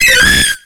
Cri de Corayon dans Pokémon X et Y.